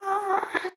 moan2.wav